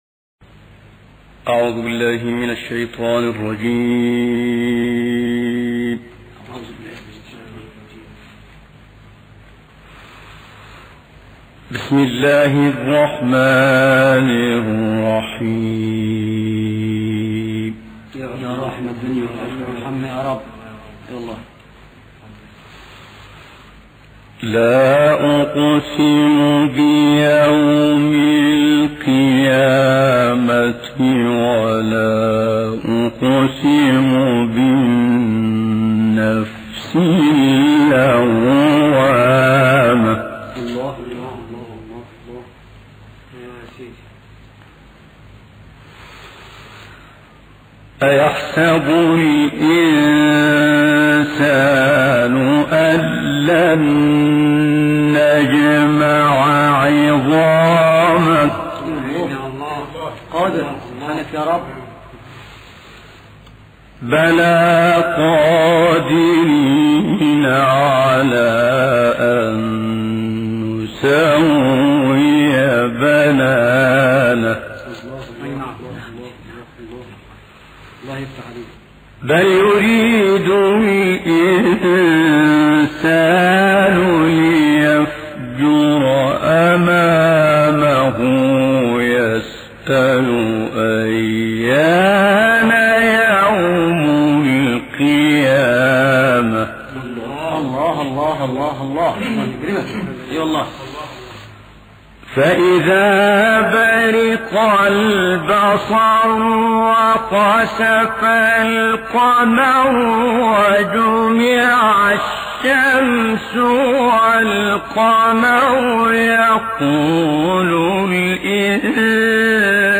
القارئ السيد متولي عبد العال -سورة القيامة.